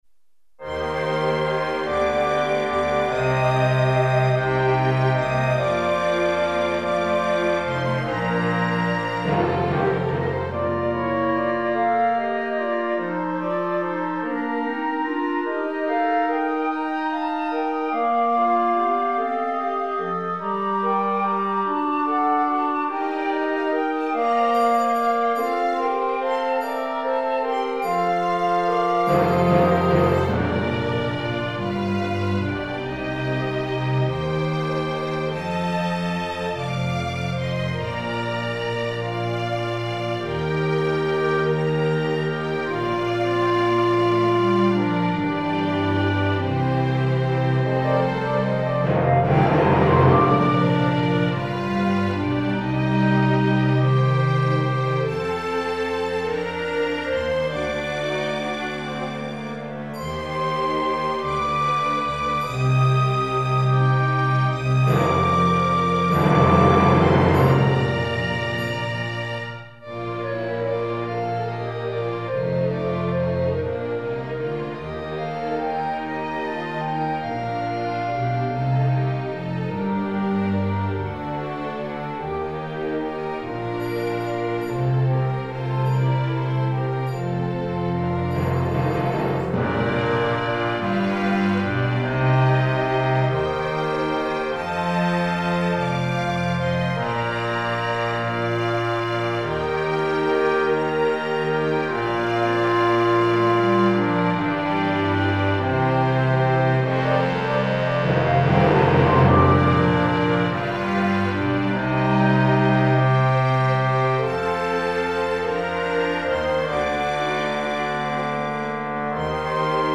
2 parts.
Triangle and Chimes
- Cello 2s  doubling Double Bass part